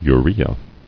[u·re·a]